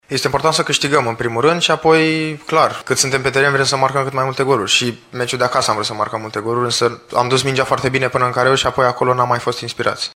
Mijlocașul Ianis Hagi vorbește despre importanța unei victorii astăzi: